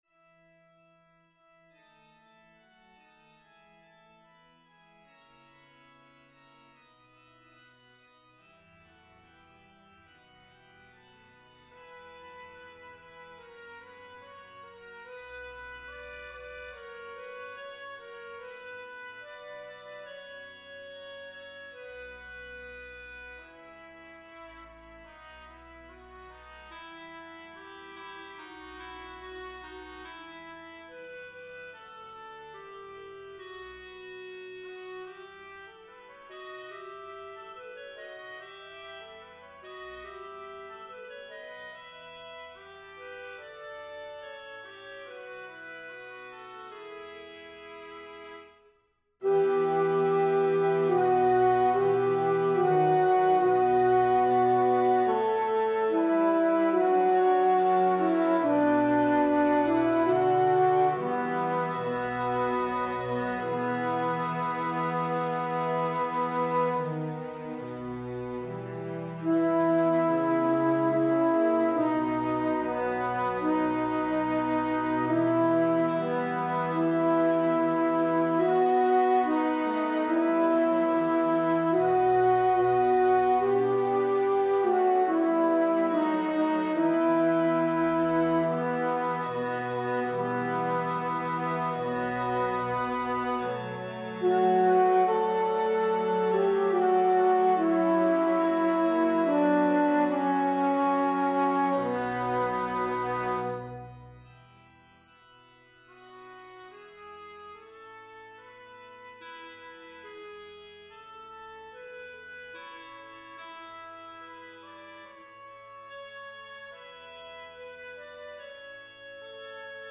Alto
Evensong Setting